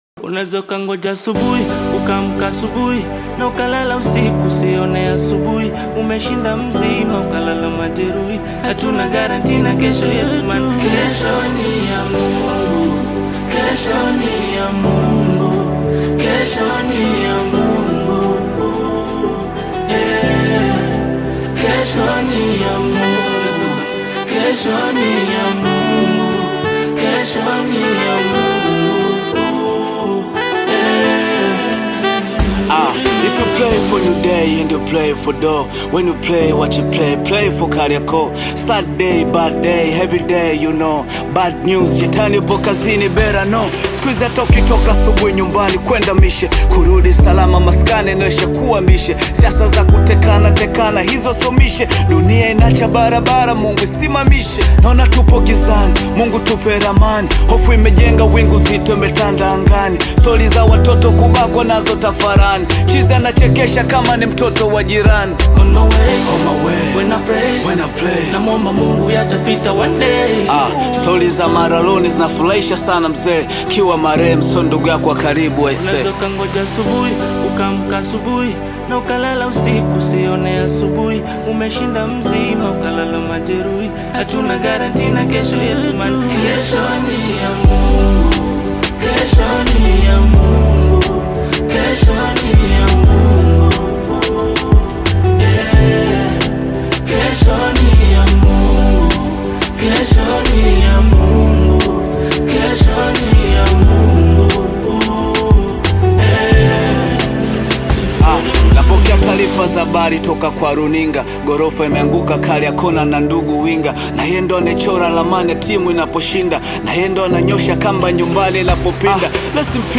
Bongo Flava song
This catchy new song